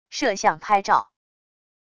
摄像拍照wav音频